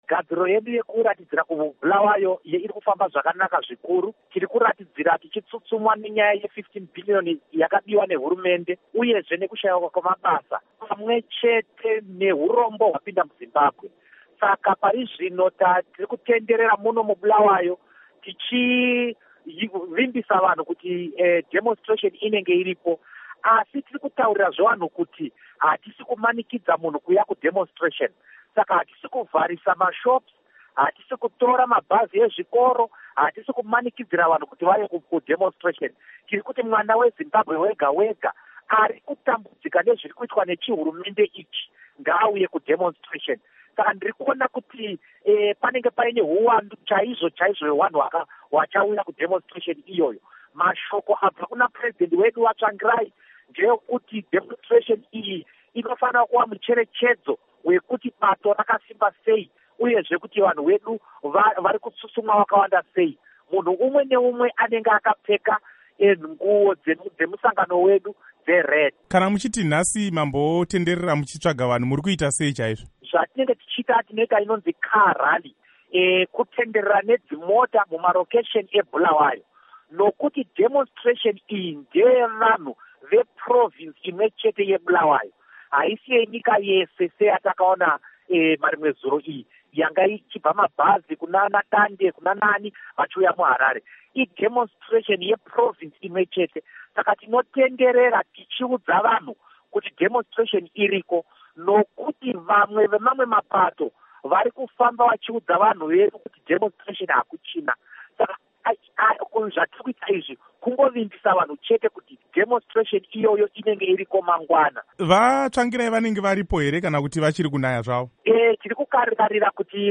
Hurukuro naVaDouglas Mwonzora